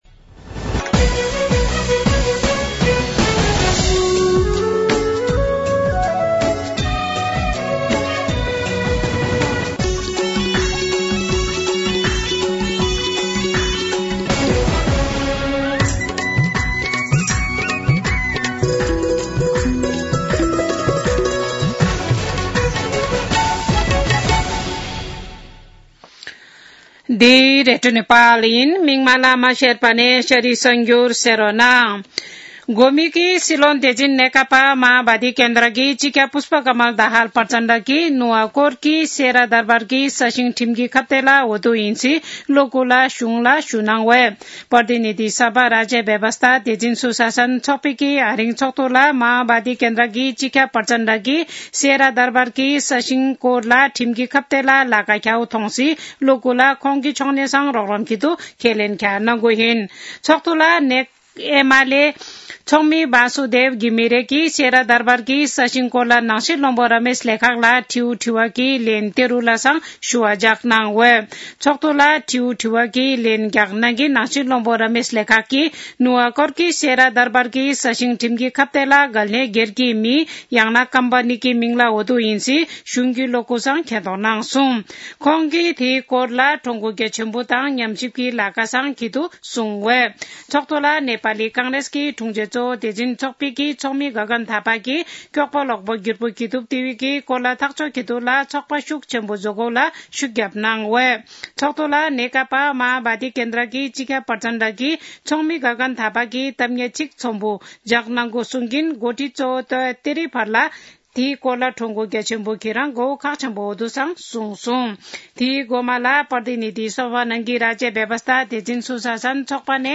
शेर्पा भाषाको समाचार : १९ पुष , २०८१
Sherpa-News.mp3